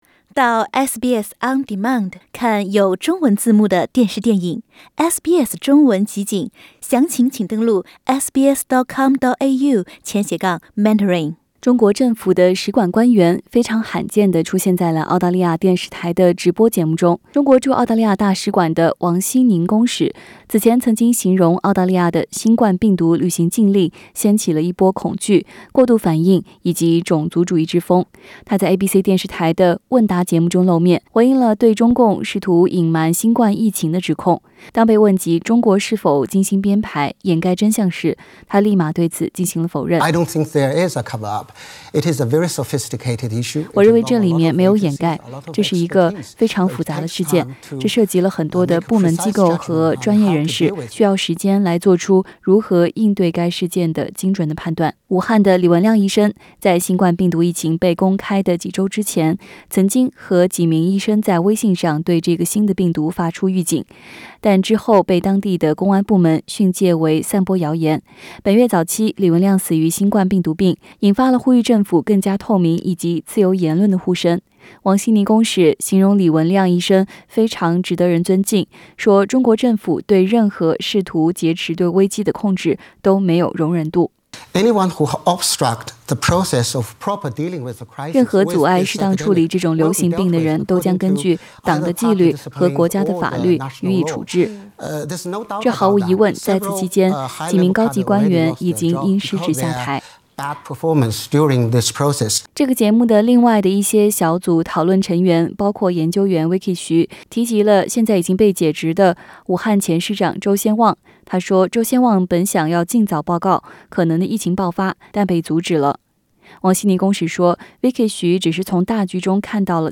SBS 普通话电台